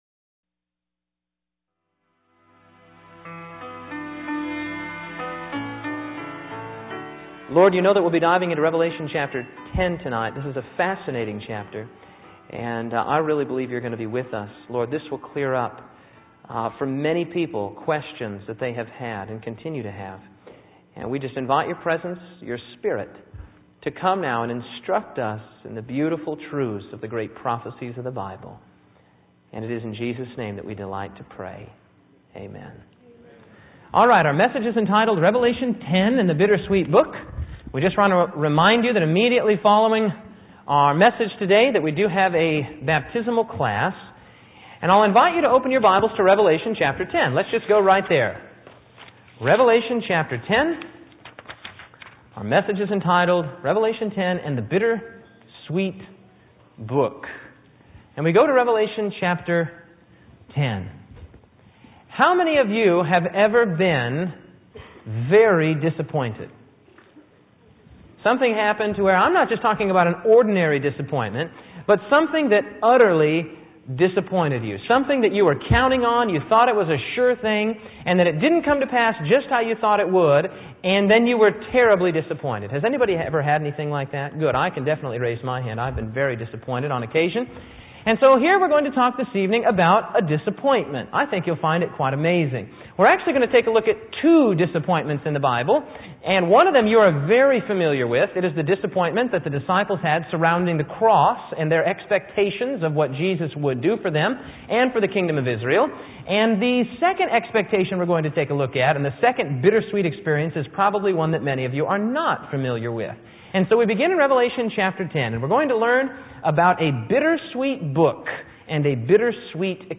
REVELATION SPEAKS – Bible Prophecy Seminar – American Christian Ministries